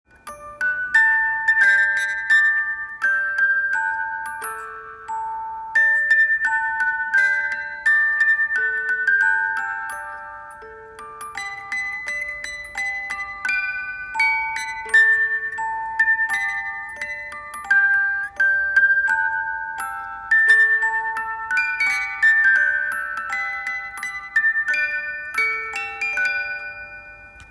１つ目はオルゴール箱です。